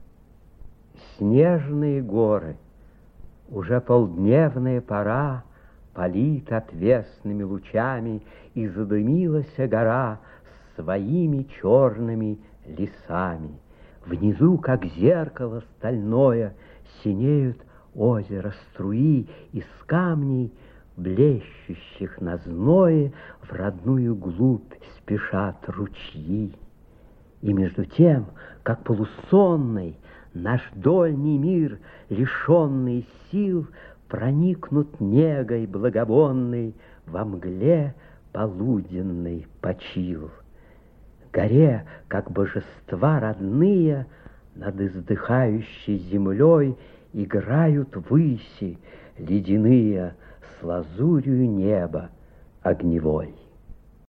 1. «(МБ) Исп. Владимир Зельдин – Ф.Тютчев – Снежные горы» /